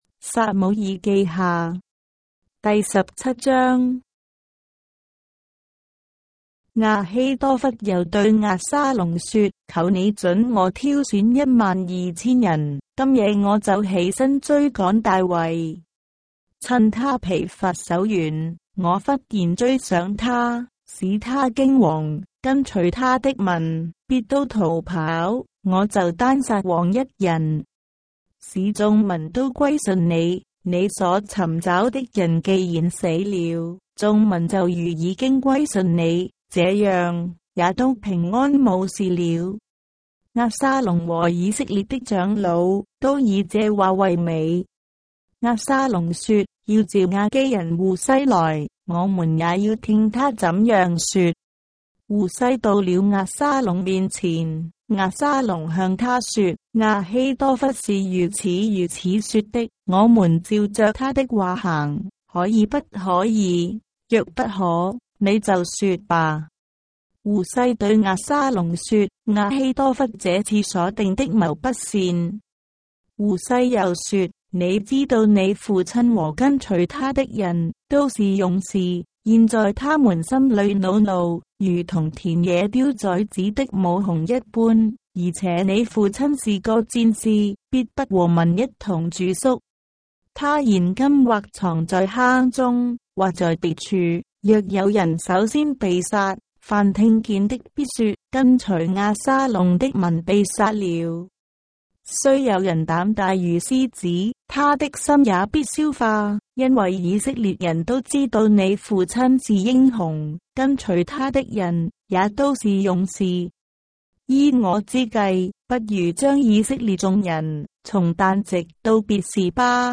章的聖經在中國的語言，音頻旁白- 2 Samuel, chapter 17 of the Holy Bible in Traditional Chinese